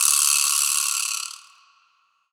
Shaker MadFlavor 2.wav